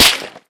light_crack_05.ogg